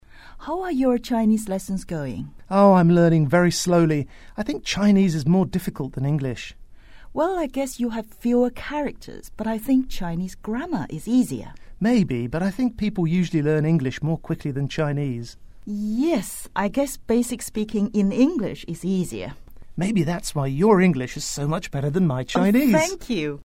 英语初学者口语对话第09集：你的中文课进展得如何了？
english_48_dialogue_2.mp3